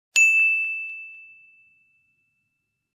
ding-sound-effect_YGfYBh6.mp3